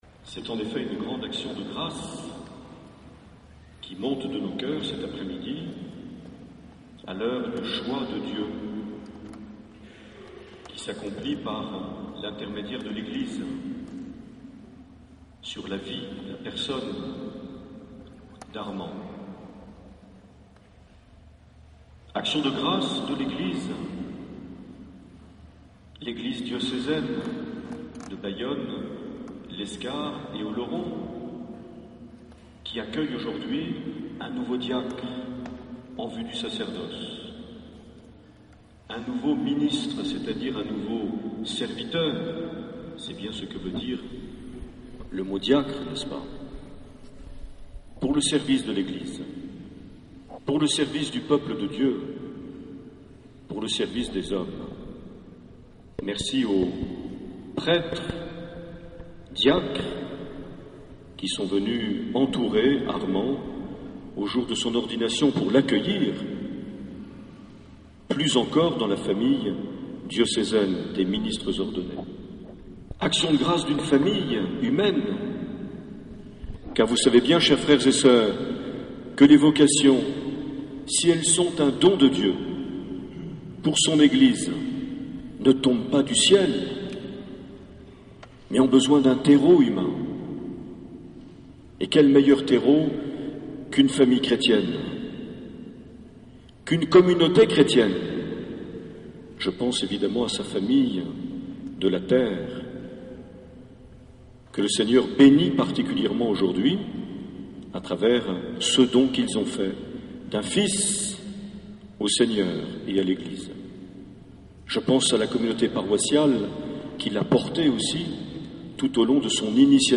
Cathédrale de Lescar - Ordination diaconale en vue du sacerdoce
Accueil \ Emissions \ Vie de l’Eglise \ Evêque \ Les Homélies \ 20 juin 2010 - Cathédrale de Lescar - Ordination diaconale en vue du (...)
Une émission présentée par Monseigneur Marc Aillet